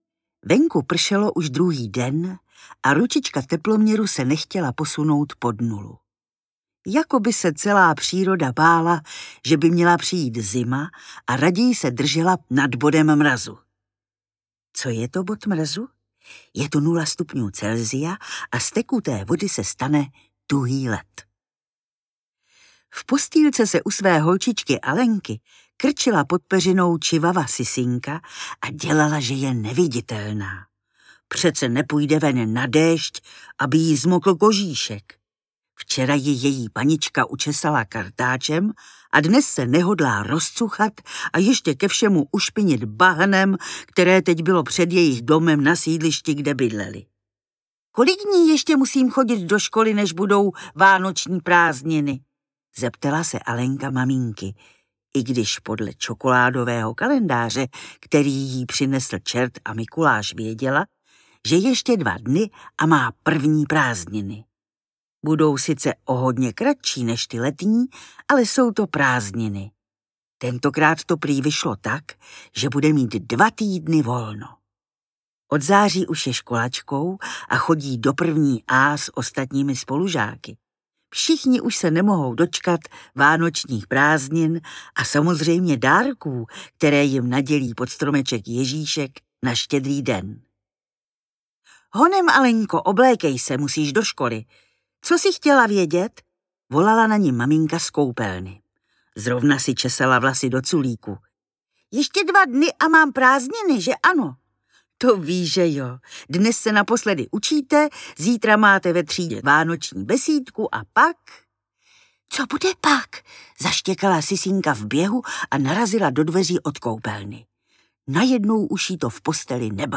Audiobook
Read: Naďa Konvalinková